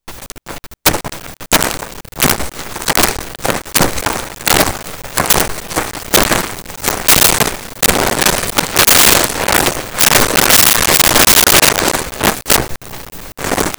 Footsteps Grass 01
Footsteps Grass 01.wav